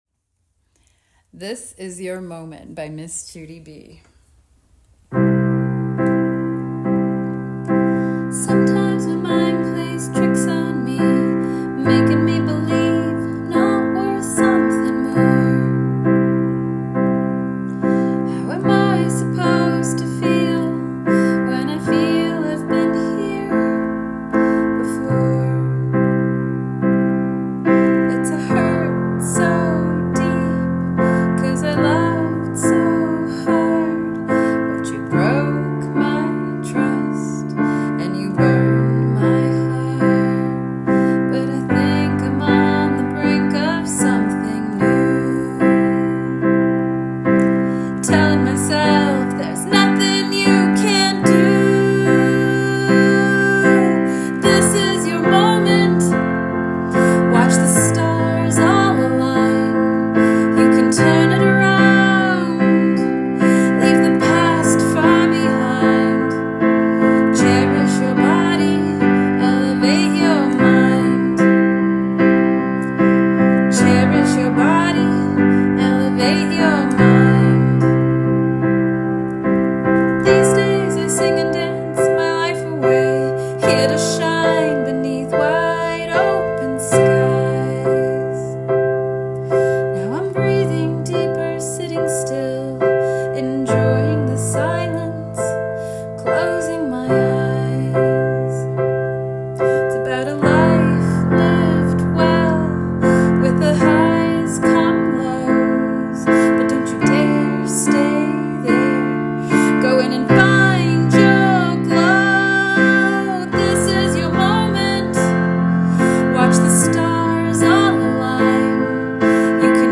This Is Your Moment is a song about the journey from heartbreak to empowerment. It begins intimate and vulnerable, carried by piano and voice, and builds into a powerful, uplifting anthem.